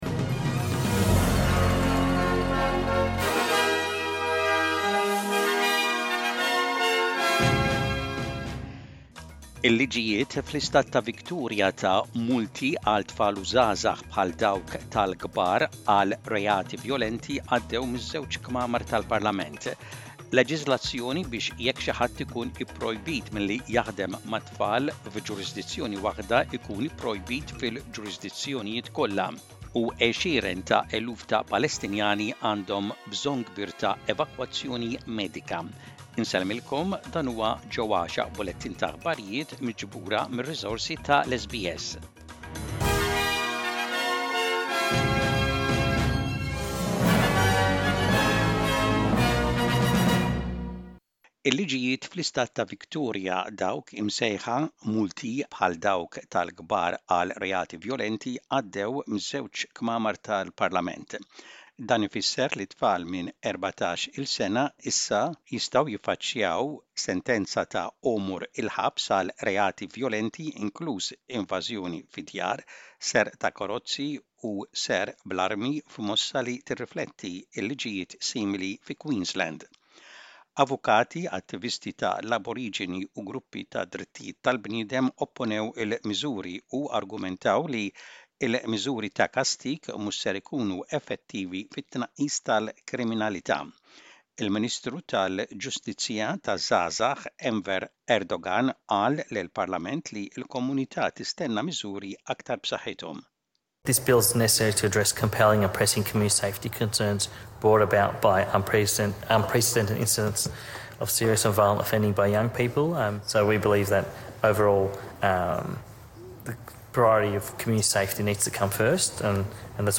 SBS Maltese News - Image by SBS Maltese